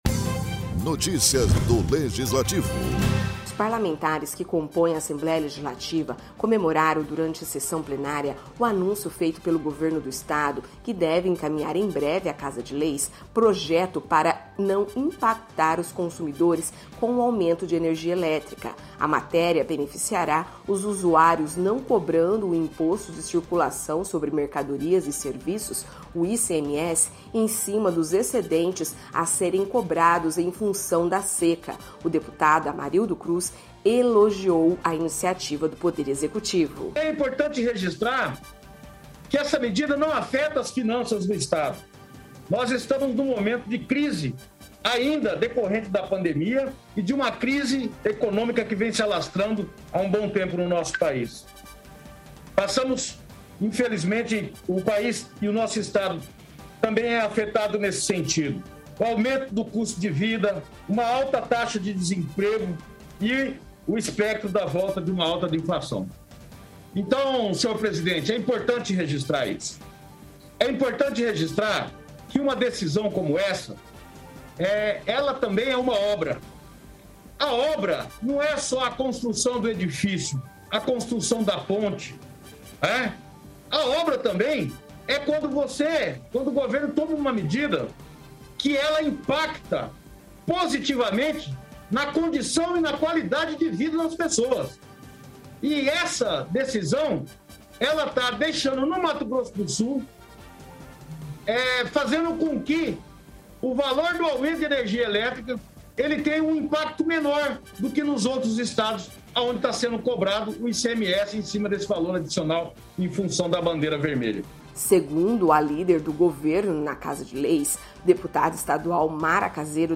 Os parlamentares que compõem a Assembleia Legislativa comemoraram durante sessão plenária o anúncio feito pelo do Governo do Estado, que deve encaminhar em breve à Casa de Leis, projeto de lei para não impactar os consumidores com o aumento de energia elétrica.
Produção e Locução